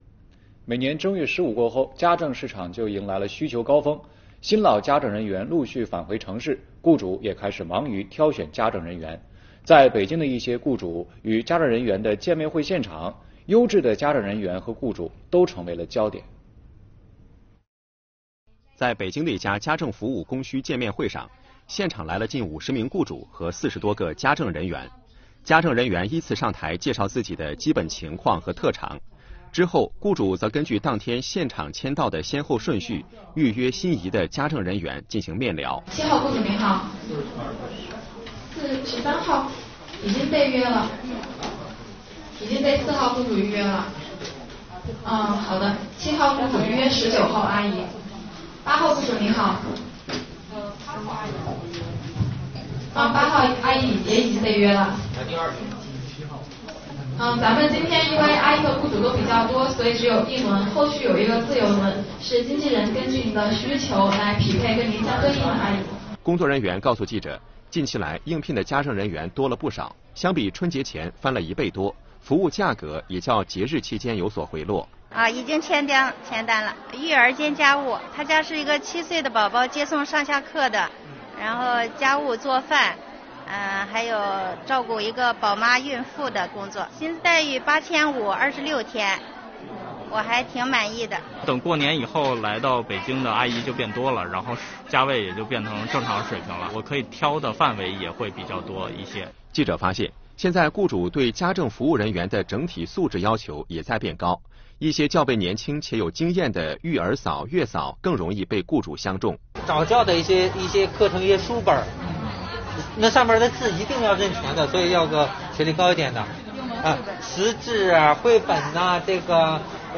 在北京的一场家政服务供需见面会上，现场来了近50名雇主和40多个家政人员，家政人员依次上台介绍自己的基本情况和特长，之后则根据当天现场雇主签到的先后顺序，预约心仪的家政人员进行面聊。